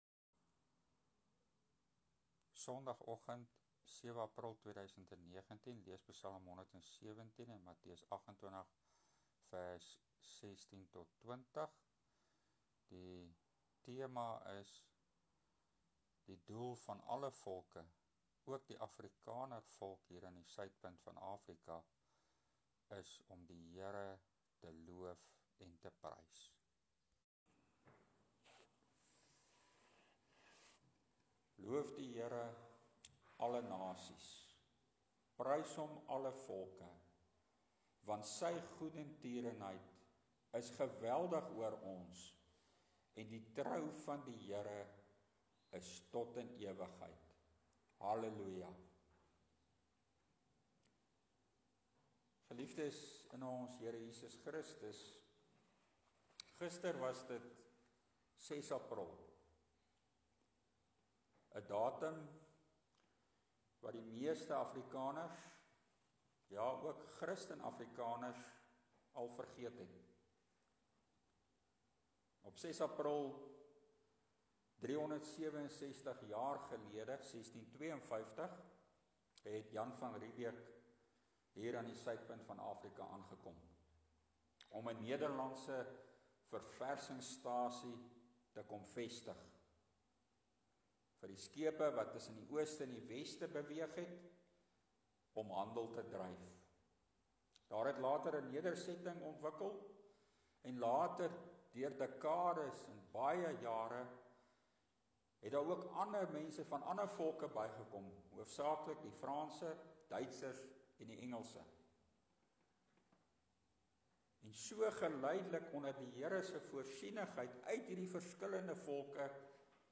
Preek: Ps. 117 Alle volke, ook die Afrikanervolk word opgeroep om die HERE te loof en te prys!
Klankopname nie te goed nie, verskoning daarvoor)